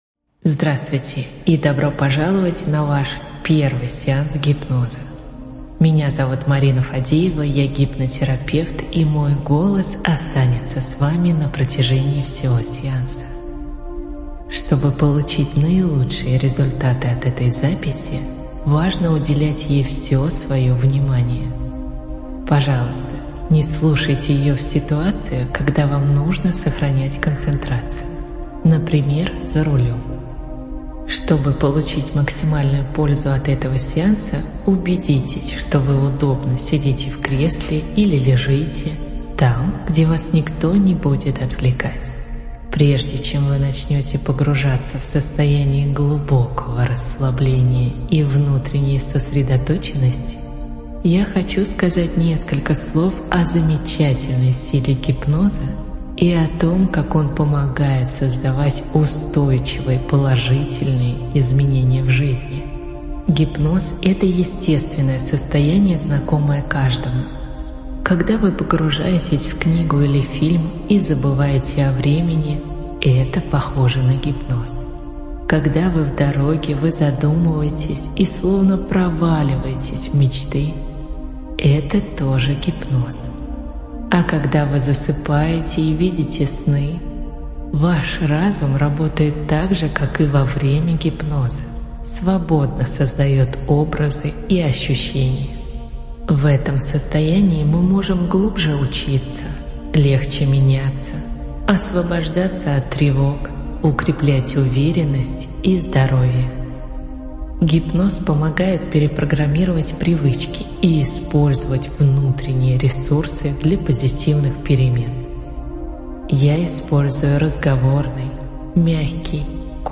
Первый сеанс гипноза 🎧 Получите БЕСПЛАТНО полный сеанс гипноза. Сеанс подходит для тех, кто никогда не пробовал гипноз; для тех, кто хочет безопасно и комфортно испытать это состояние; для людей, которым важно научиться расслабляться и управлять стрессом. 1 0 ₽ Начните прослушивание прямо здесь или скачайте запись.
Первый_сеанс_гипноза.mp3